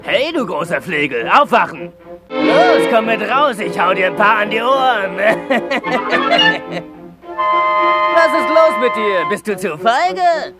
böse Katze      ...